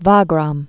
(vägräm)